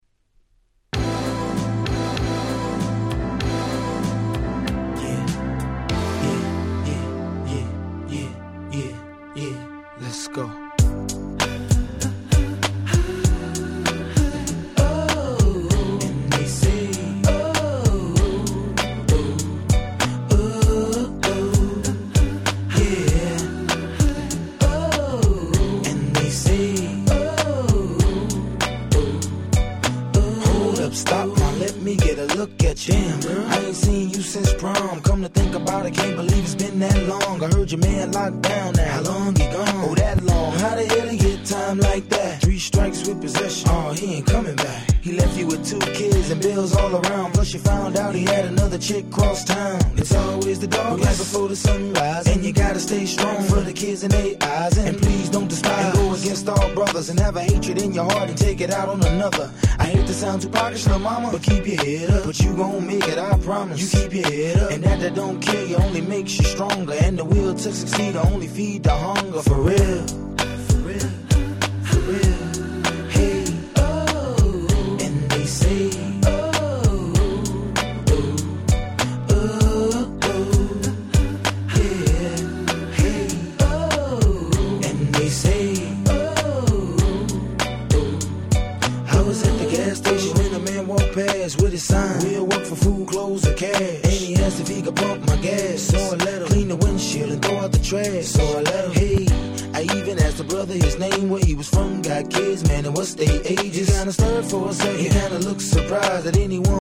05' Smash Hit Hip Hop !!